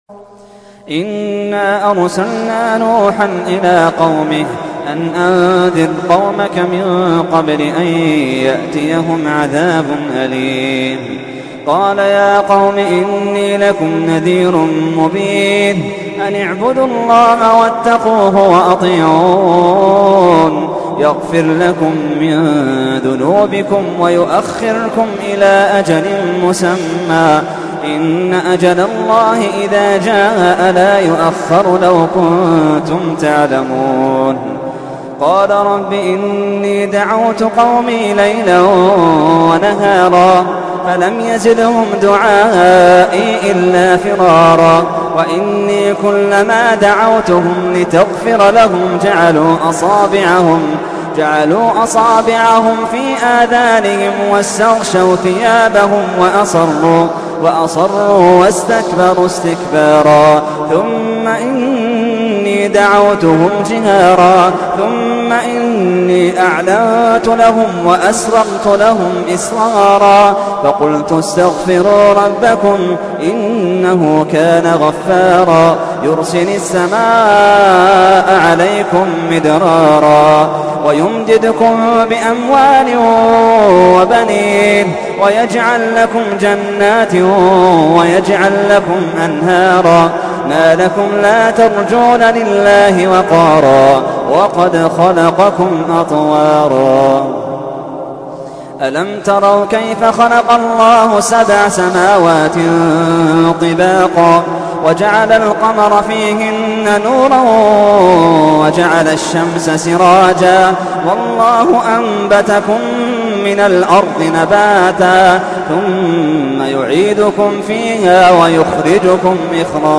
تحميل : 71. سورة نوح / القارئ محمد اللحيدان / القرآن الكريم / موقع يا حسين